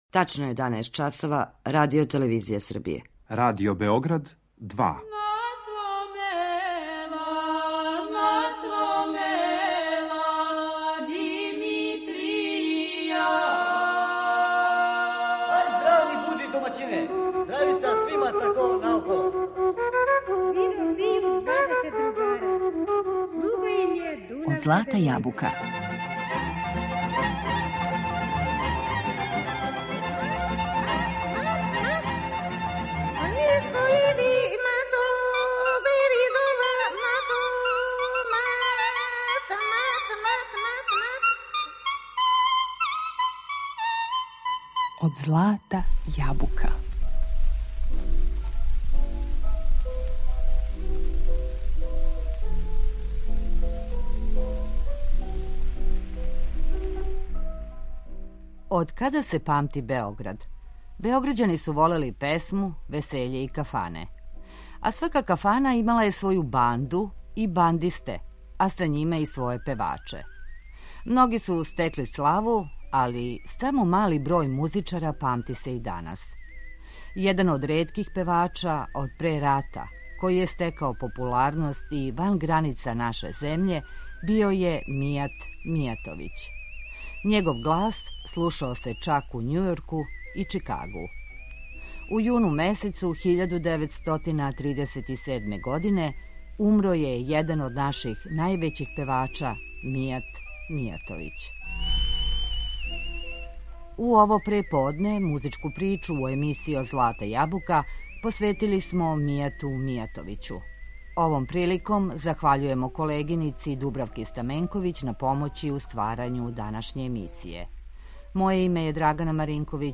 За Мијата Мијатовића, једног од водећих певача до 1941. године, каже се да је представљао типичан пример београдског певања народне музике, које се у жаргону звало „асталско” певање.